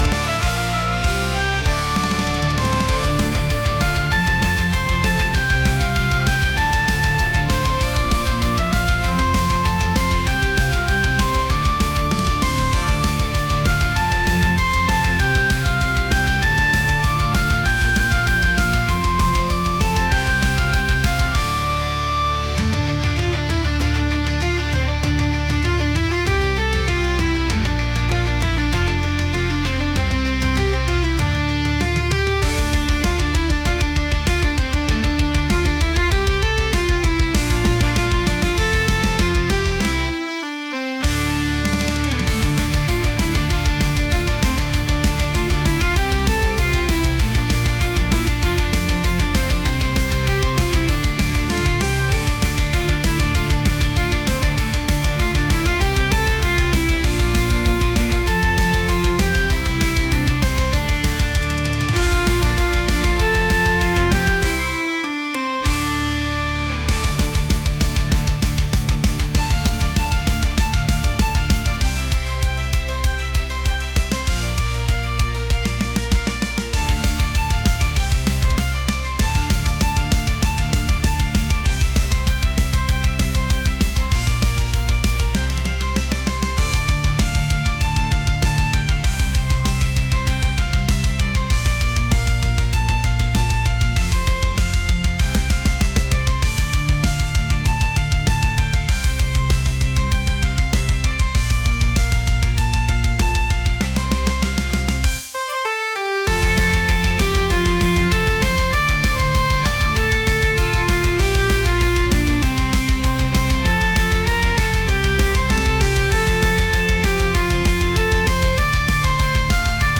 metal | energetic